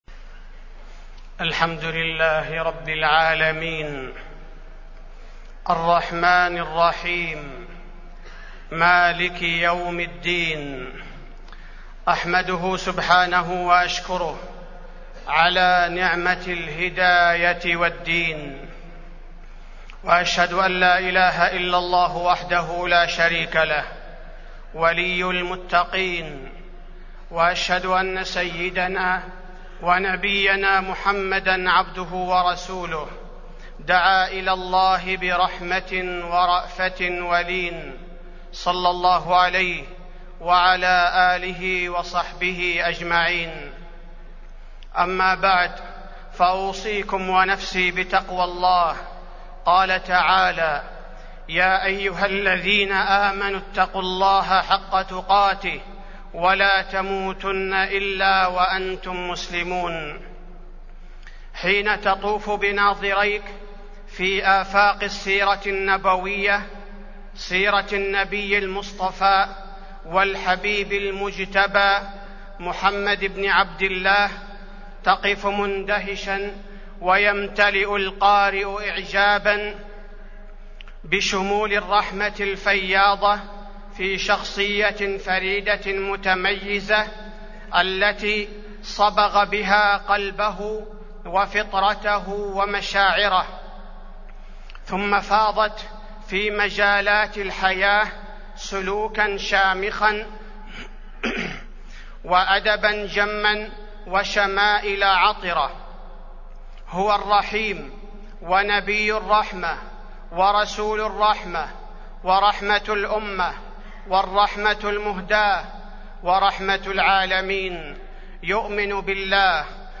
تاريخ النشر ١٠ ذو القعدة ١٤٢٧ هـ المكان: المسجد النبوي الشيخ: فضيلة الشيخ عبدالباري الثبيتي فضيلة الشيخ عبدالباري الثبيتي الرحمة المهدة صلى الله علية وسلم